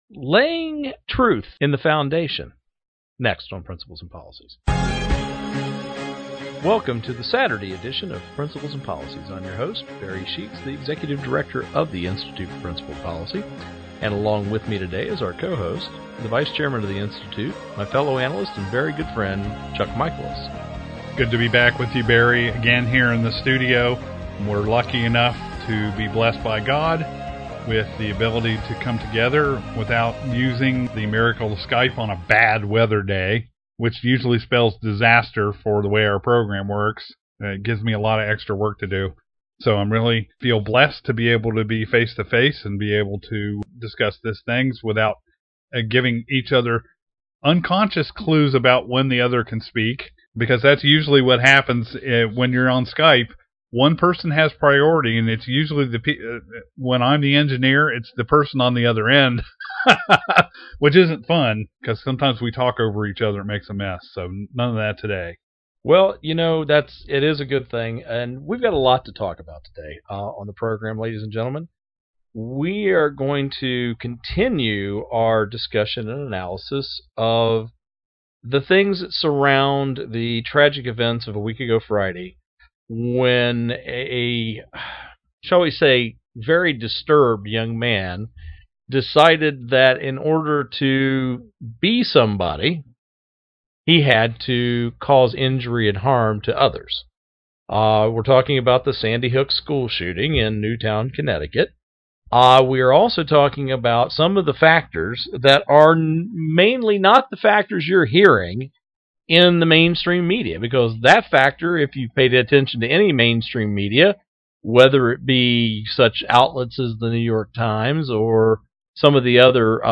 Our Principles and Policies radio show for Wednesday December 19, 2012.